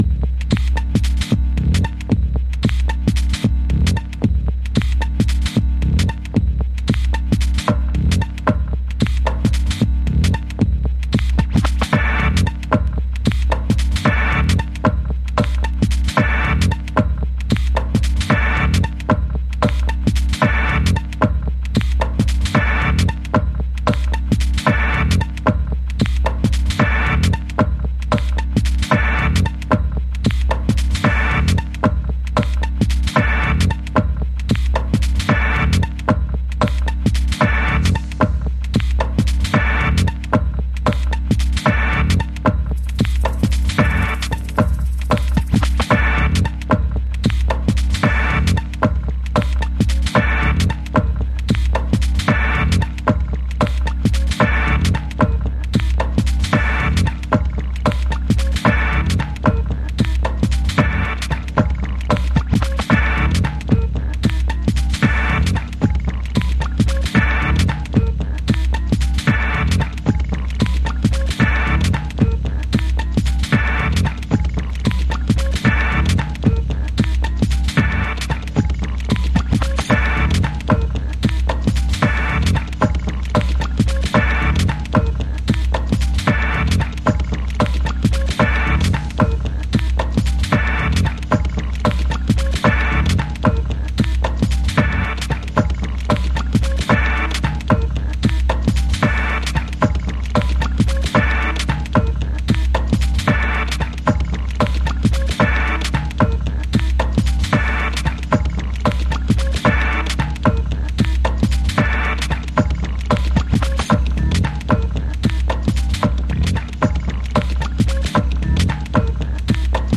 サンプリングメインでつくり上げられた、粒子浮き立つRAW HOUSE TRACKS。